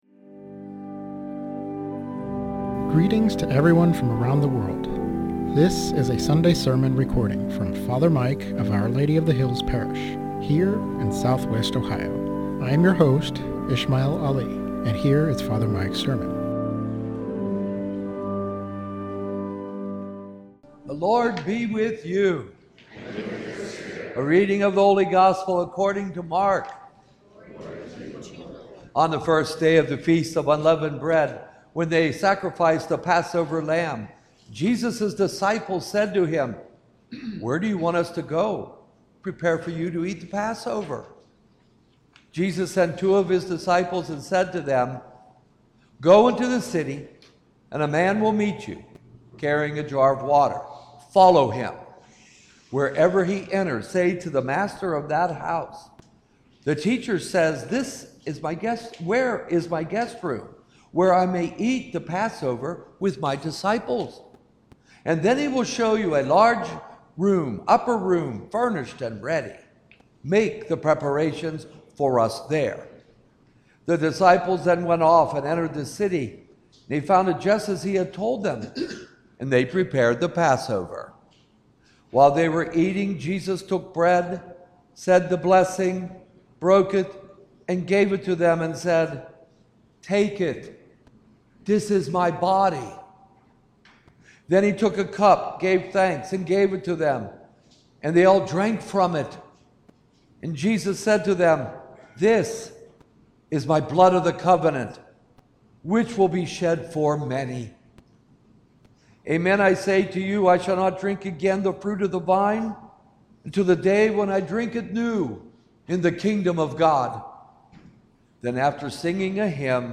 SERMON ON MARK 14:22-26